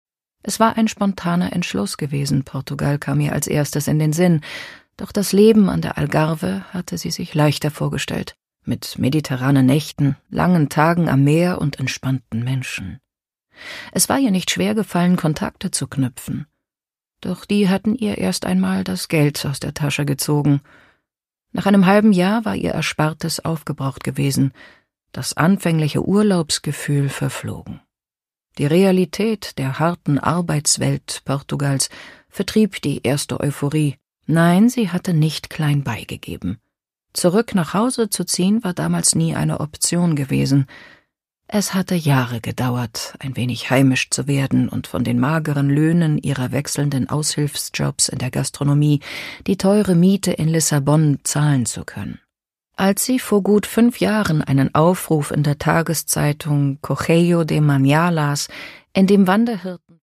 Produkttyp: Hörbuch-Download
Gelesen von: Tessa Mittelstaedt
Als Hörbuchsprecherin weiß sie Temperamente und Stimmungen von nordisch-kühl bis herzlich gekonnt auszudrücken.